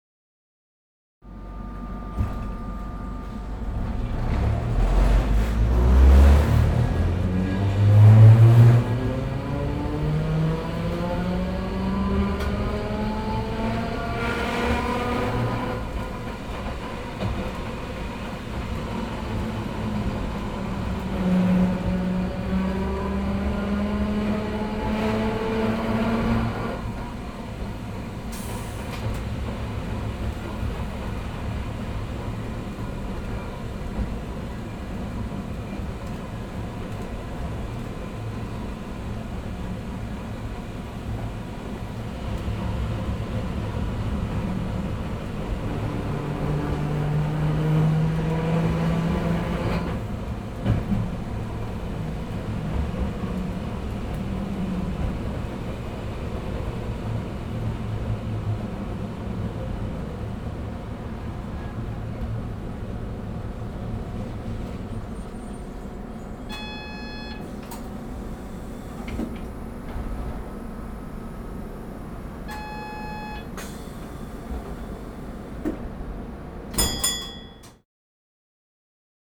機械のモーター音やアナウンス、発車の合図......。地下鉄、都電荒川線、都営バスのそれぞれの場所でしか聞くことができない音を収録しました。
第5回都電荒川線「7000形走行音」
第5回 都電荒川線「7000形走行音」 荒川線を走る電車のうち7000形電車は、吊り掛け駆動式のモーターを採用しています。足底から響いてくる、ブーンという唸るようなモーター音が特徴的です。